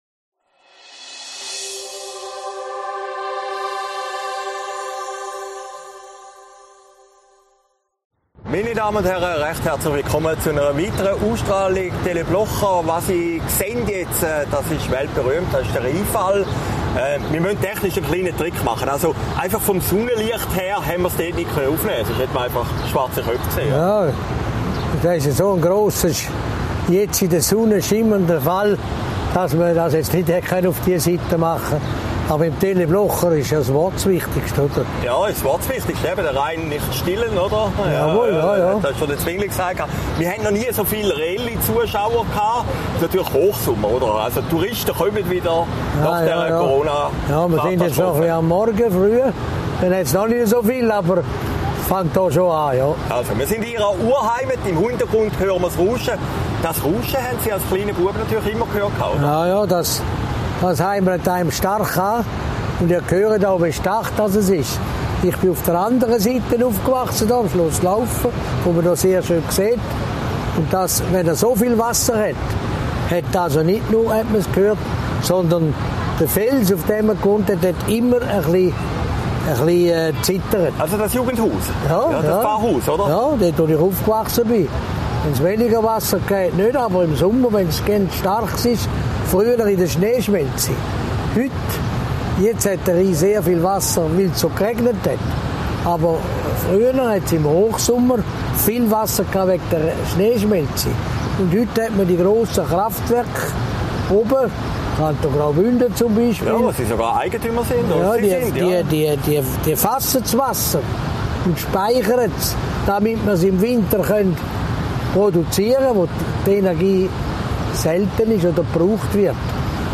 Sendung, 6. August 2021, Rheinfall